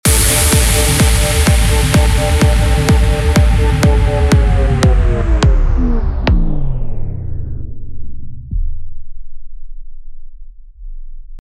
Однако, поскольку это эмуляция винила, а у мотора в виниловом проигрывателе есть инерция, то остановка музыки может происходить не сразу.
Пауза в режиме Vinyl
vinyl-mode-stop.mp3